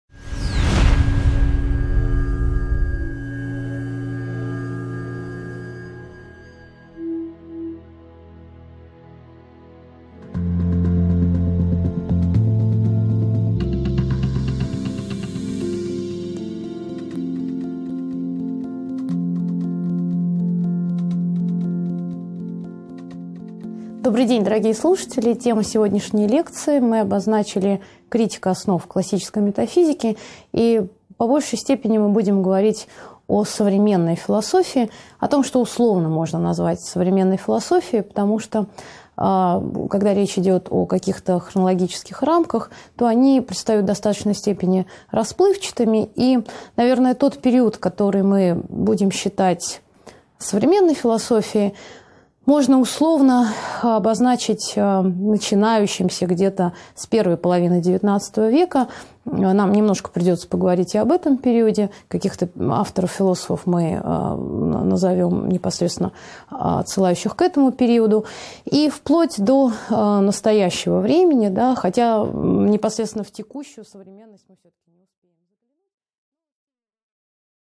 Аудиокнига 14.1 Определение метафизики. Метафизика vs Позитивизм | Библиотека аудиокниг